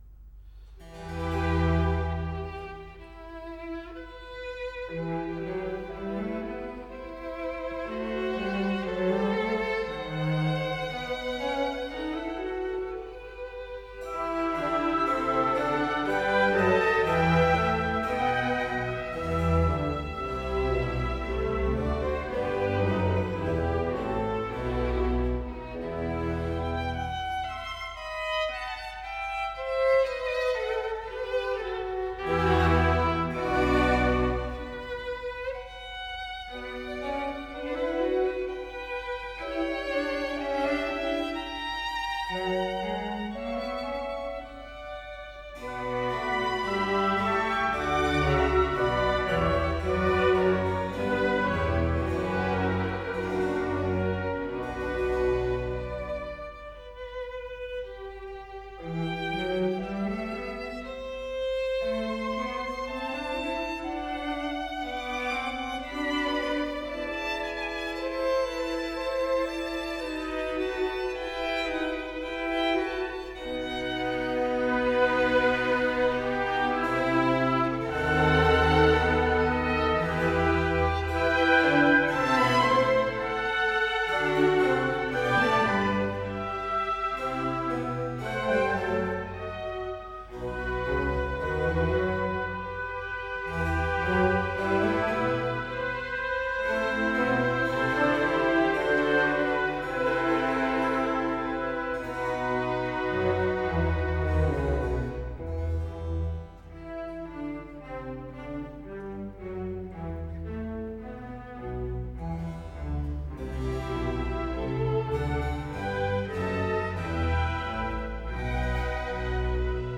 in G major - Adagio